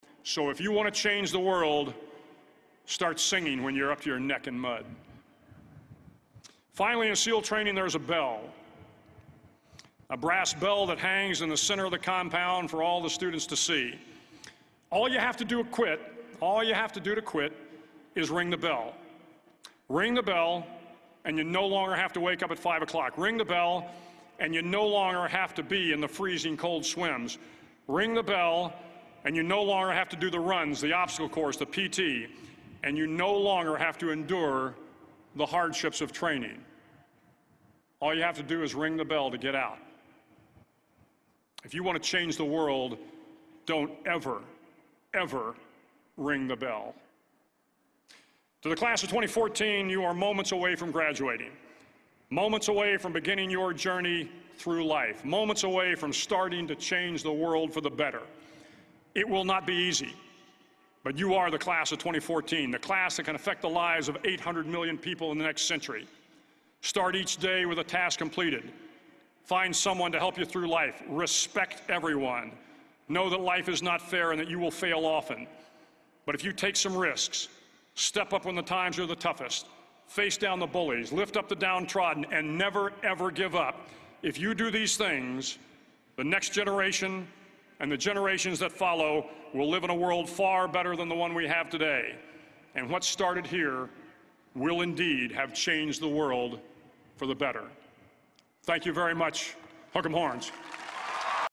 公众人物毕业演讲 第240期:威廉麦克雷文2014德州大学演讲(12) 听力文件下载—在线英语听力室